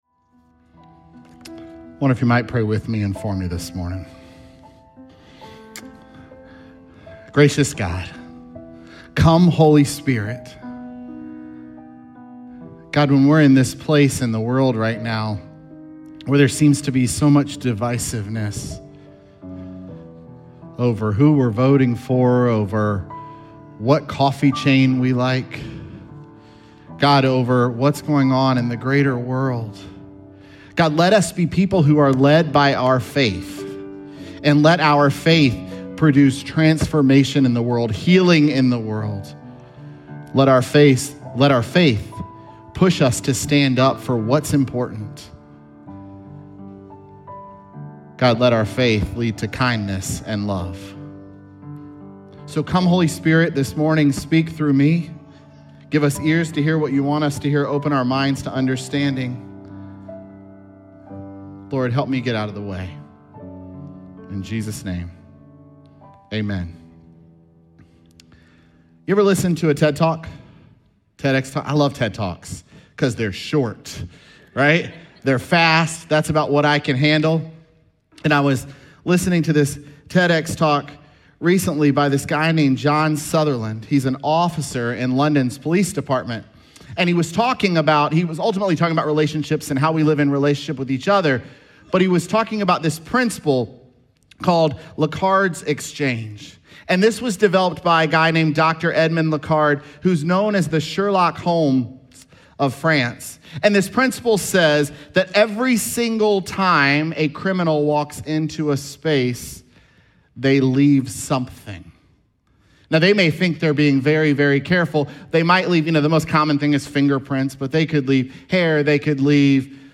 Sermons
Oct20SermonPodcast.mp3